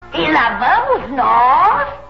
Episódio da Vassoura da Bruxa do Pica-Pau na qual a bruxa repete a todo momento "e lá vamos nós!"